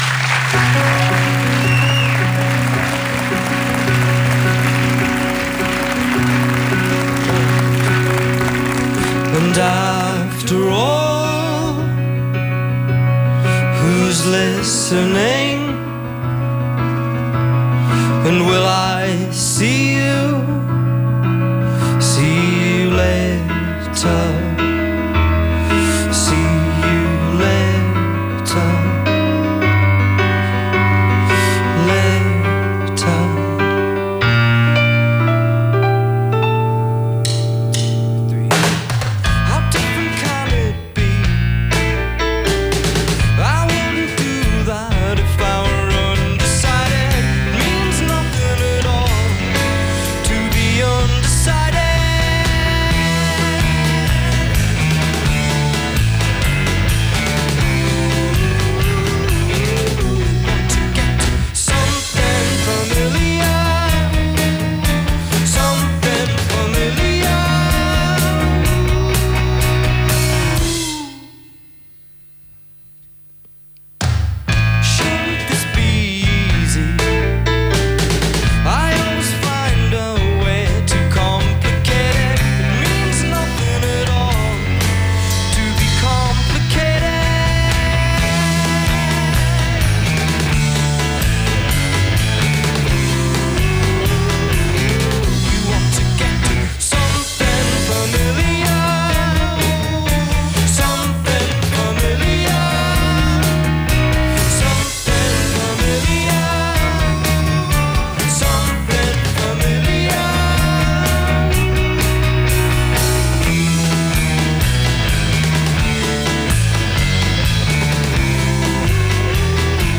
enregistrée le 22/02/2010  au Studio 105